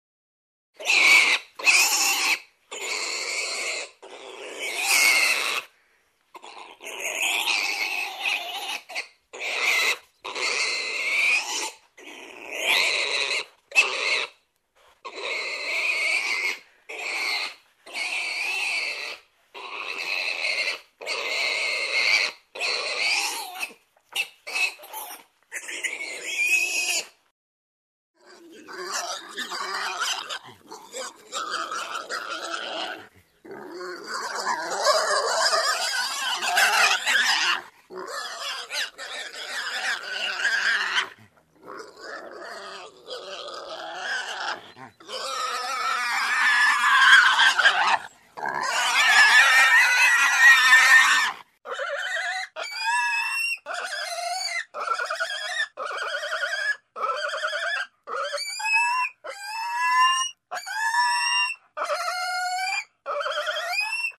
На этой странице собраны разнообразные звуки, которые издают свиньи: от забавного хрюканья до громкого визга.
Звук визга маленького поросенка